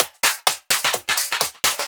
Index of /VEE/VEE2 Loops 128BPM
VEE2 Electro Loop 391.wav